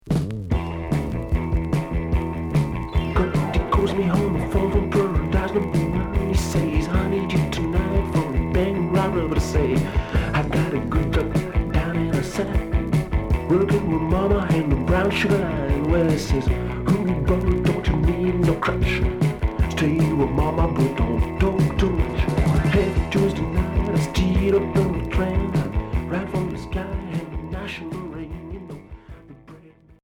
Rock glam Troisième 45t retour à l'accueil